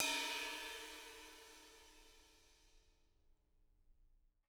R_B China 02 - Room.wav